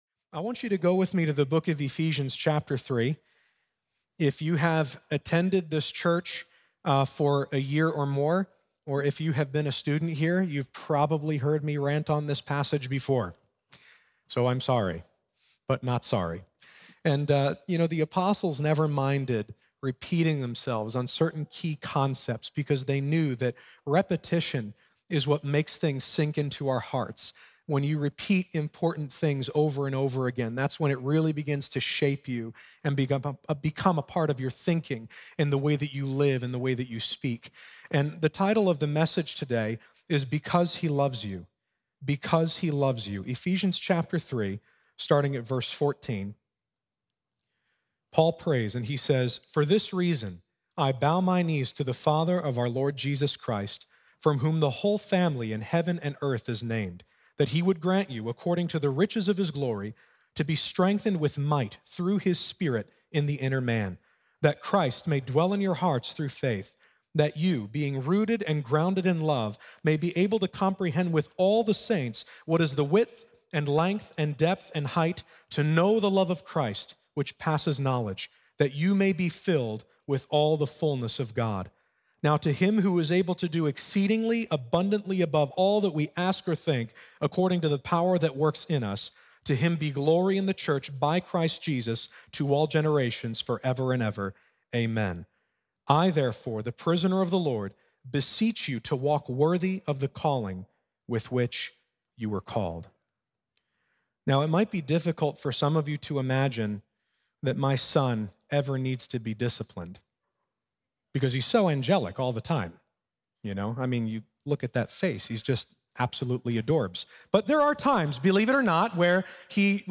Because He Loves You | Times Square Church Sermons